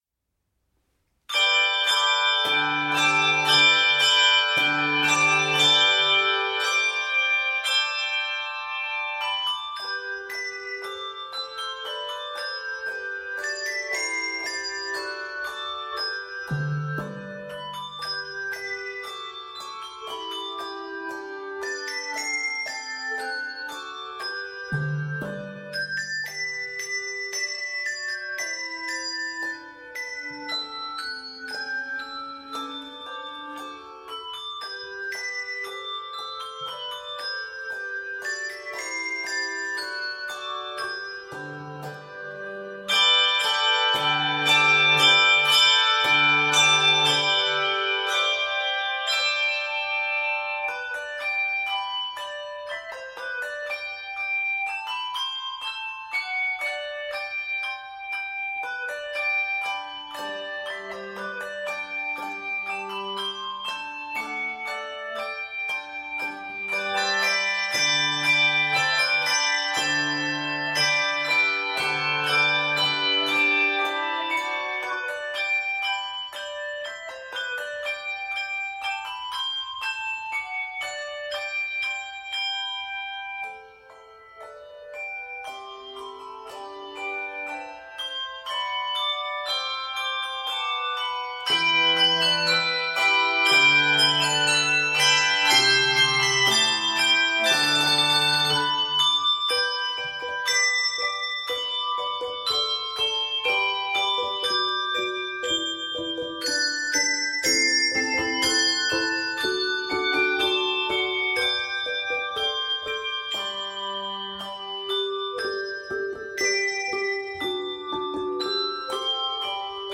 is an uplifting arrangement